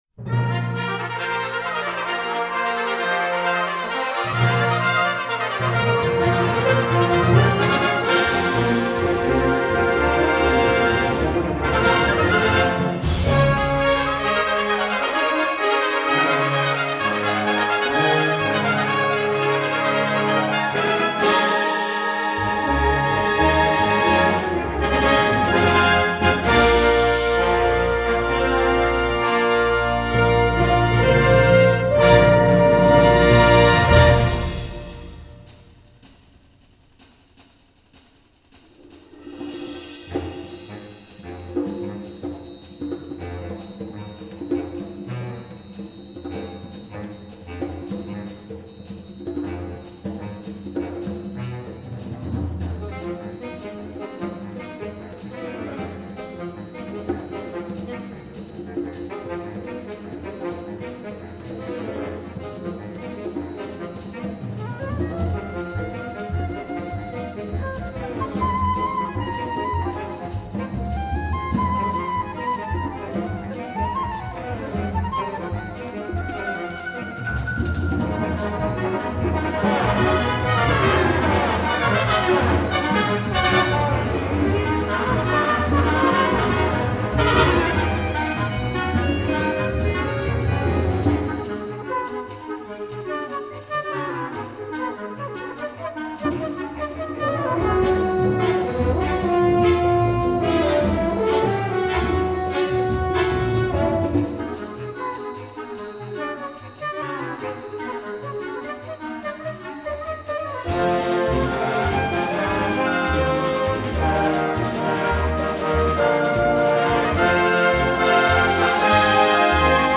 7:10 Minuten Besetzung: Blasorchester PDF